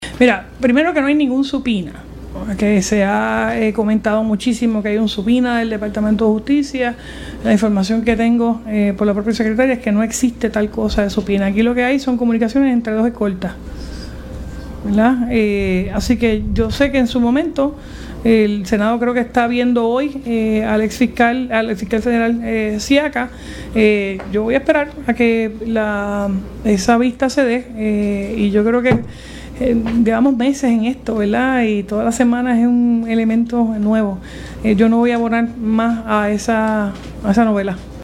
La gobernadora Jenniffer González opinó hoy durante una conferencia de prensa en Isabela que la controversia de su designada Secretaria de Justicia, Janet Parra es una “novela”.